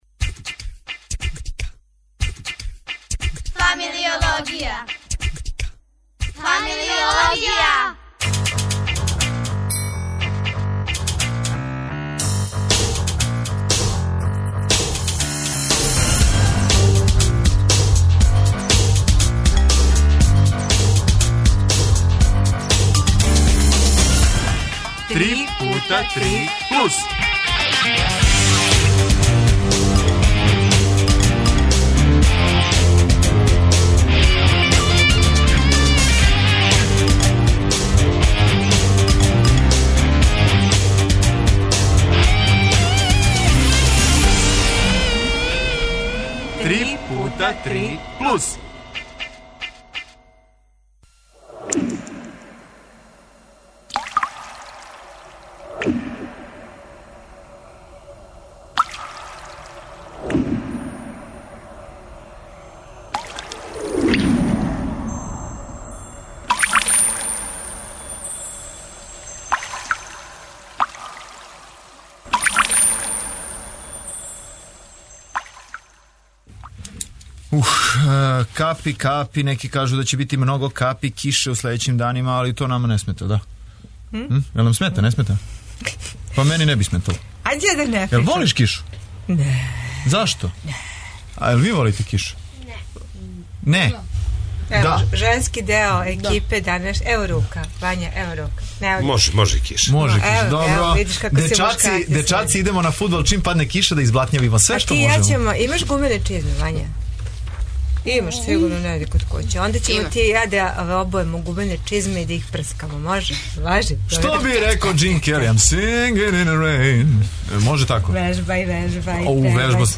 Наши гости у студију су такође мајстори - у изради облика и малих грађевина од лего коцкица...